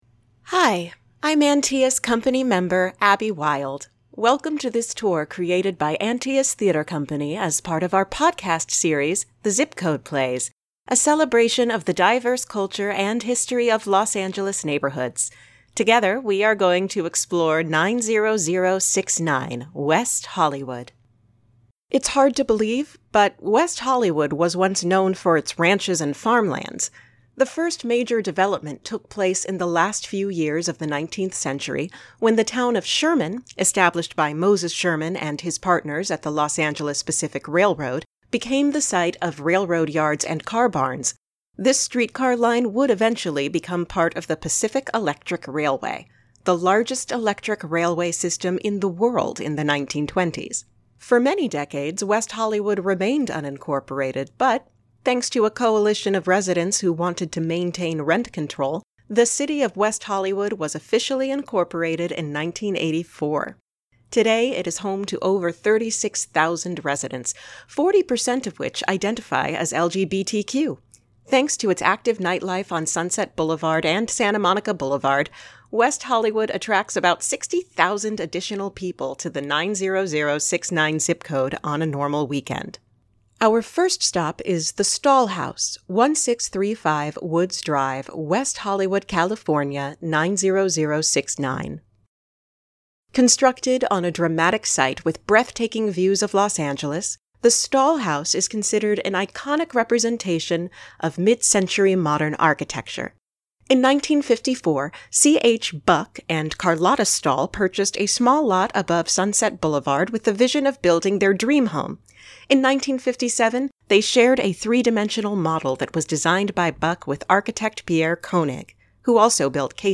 Download Full Tour Audio